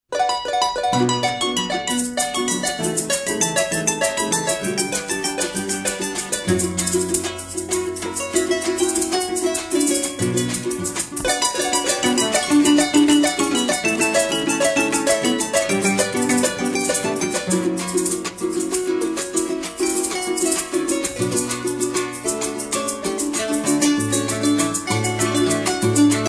Harpe hanera, de Llanos
Pièce musicale éditée